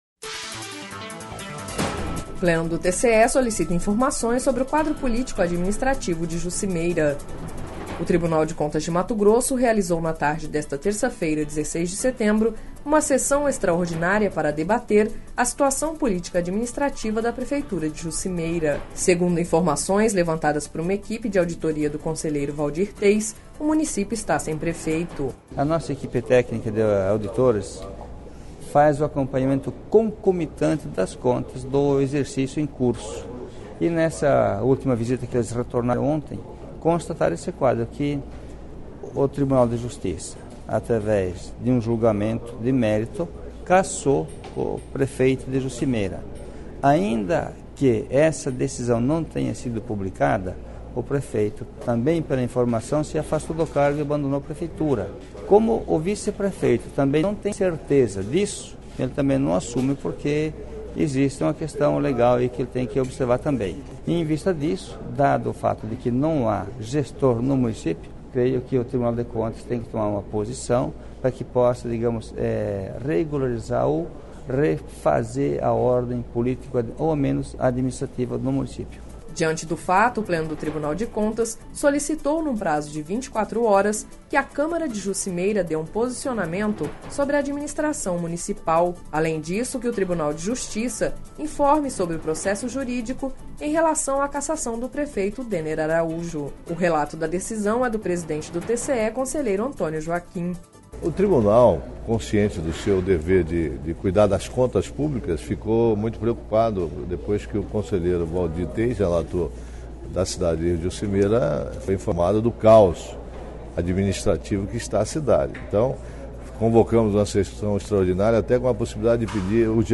Sonora: Waldir Teis - conselheiro do TCE-MT
Sonora: Antonio Joaquim – conselheiro presidente do TCE-MT